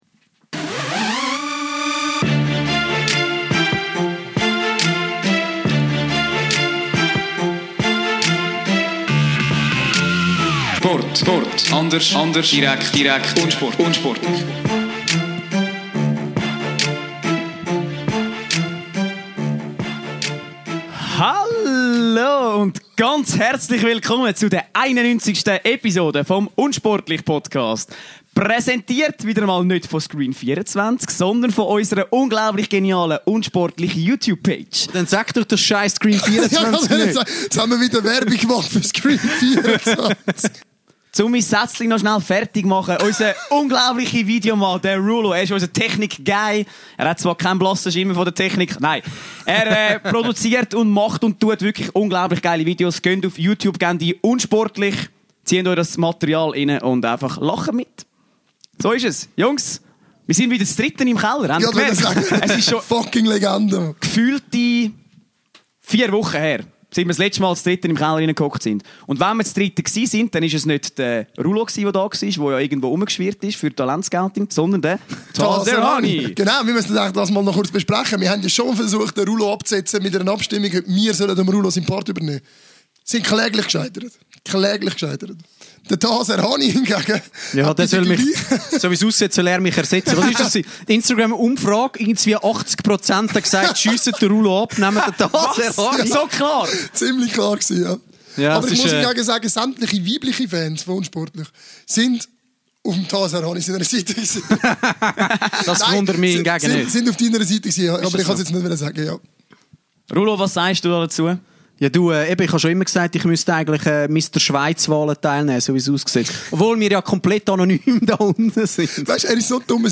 13.04.2019 – Wieder zu dritt im Keller vereint – das erste Mal seit Wochen! Der heutige Podcast steht ganz unter dem Stern von Conor McGregor.